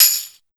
BIG125TAMB-R.wav